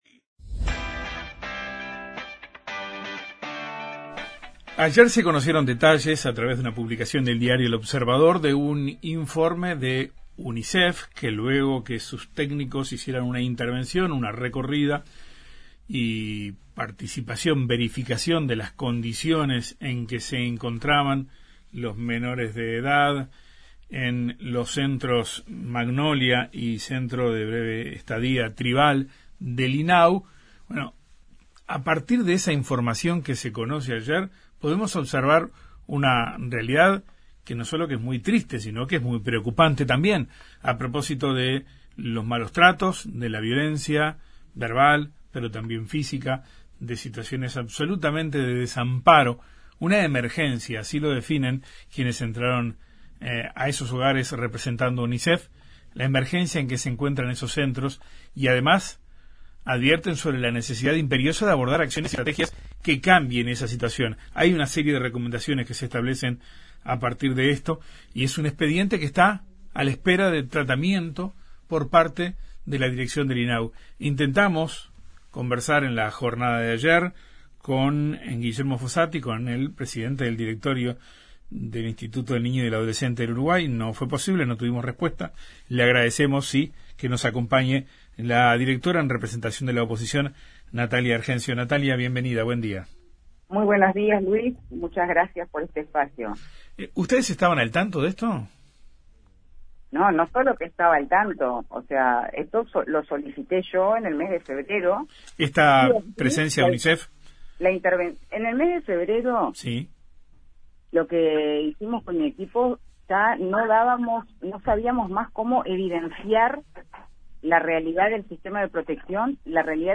Entrevista con la directora del Instituto del Niño y Adolescente de Uruguay (Inau) en representación de la oposición, Natalia Argenzio